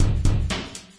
Drum Percussion Loops " Drum Loop Rock01 120 Reverb02
Tag: 回路 命中 岩石 节奏 冲击 120-BPM 打击乐器 击败 打击乐器环 量化 鼓环 常规